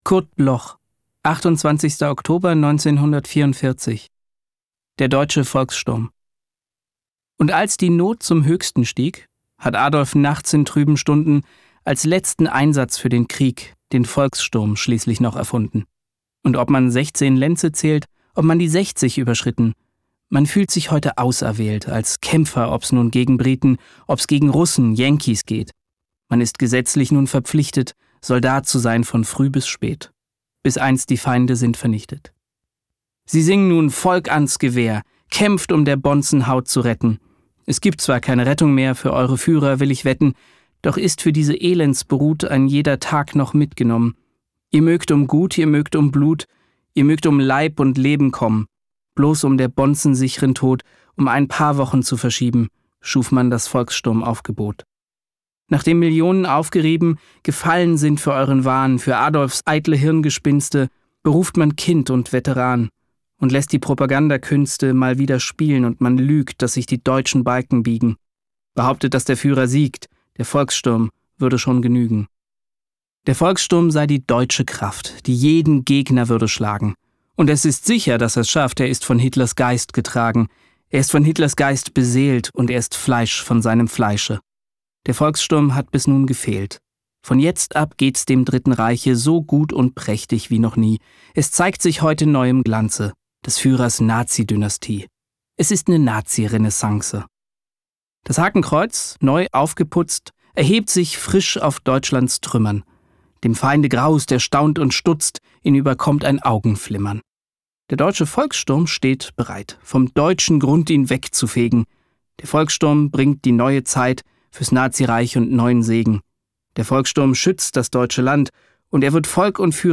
Sebastian Urzendowsky (* 1985) ist ein deutscher Schauspieler.
Aufnahme: der apparat multimedia, Berlin · Bearbeitung: Kristen & Schmidt, Wiesbaden